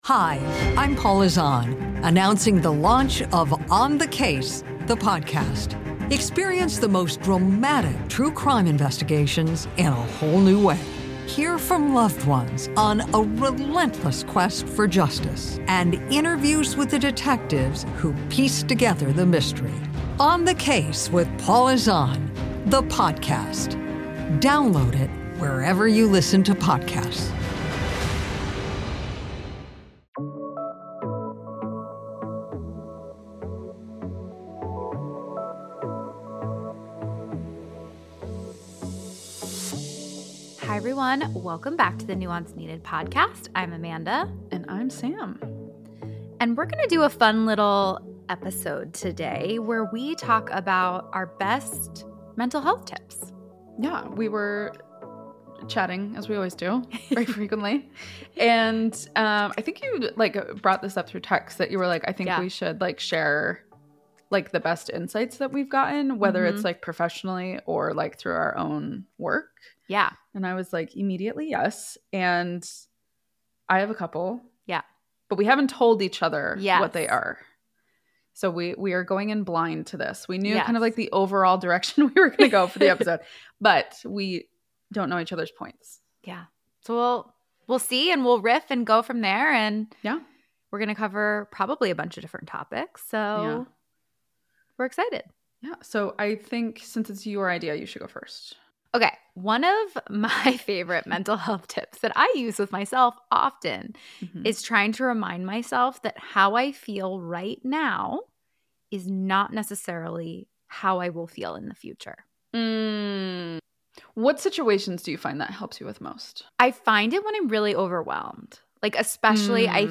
They discuss how current feelings can distort perceptions of the past and future, and emphasize the need for personal responsibility in navigating life's challenges. The conversation highlights the significance of recognizing one's role in various dynamics and the power of reframing thoughts to foster personal growth and emotional well-being.